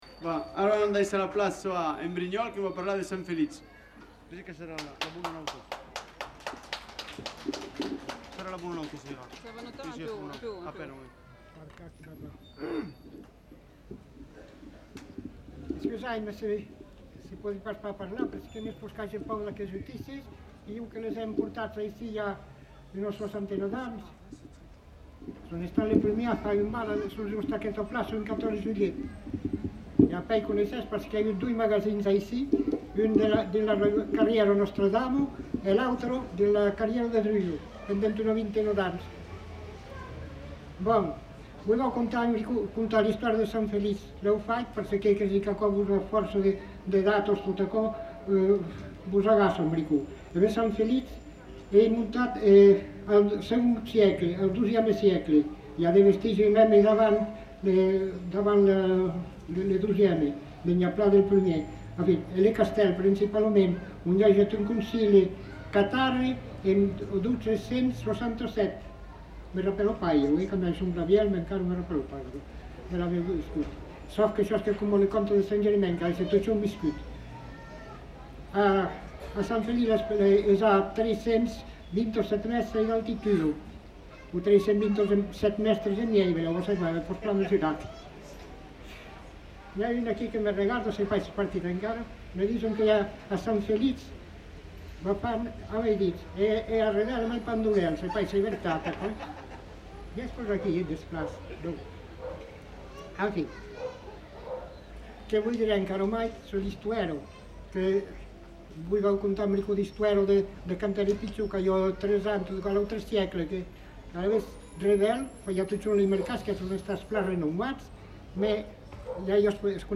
Lieu : Revel
Genre : parole